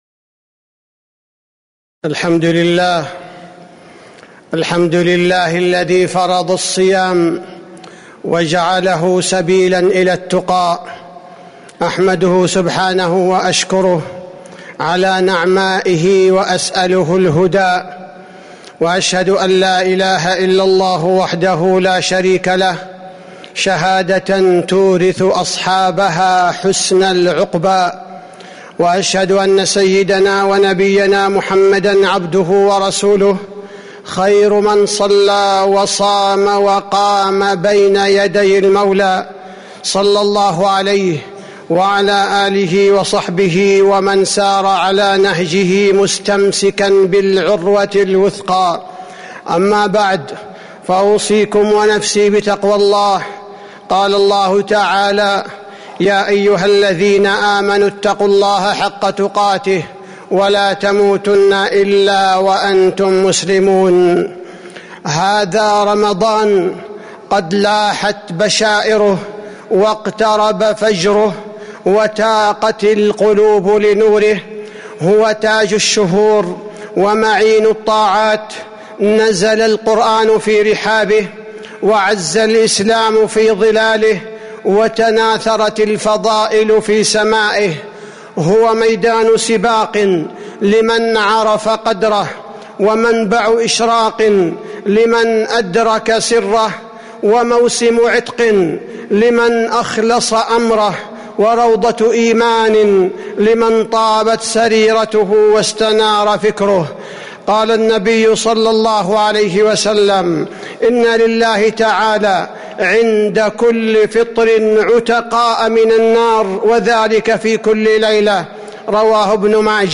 تاريخ النشر ٢٢ شعبان ١٤٤٦ هـ المكان: المسجد النبوي الشيخ: فضيلة الشيخ عبدالباري الثبيتي فضيلة الشيخ عبدالباري الثبيتي الاستعداد لاستقبال الشهر الفضيل